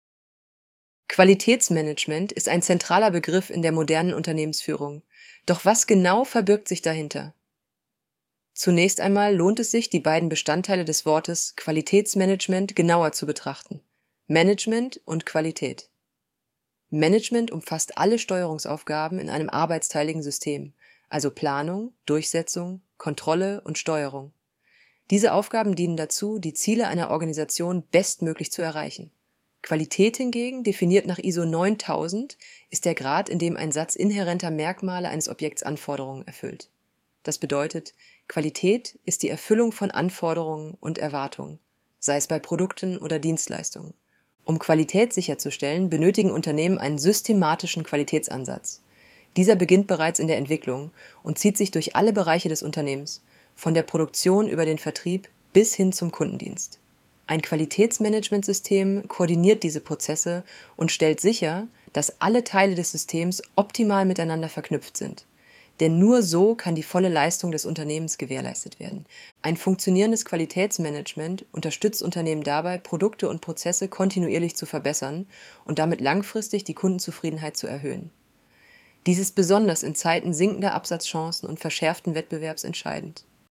Hinweis: Diese Vorlesefunktion verwendet eine synthetisch erzeugte Stimme aus einem KI-System.Die Stimme ist keine Aufnahme einer realen Person.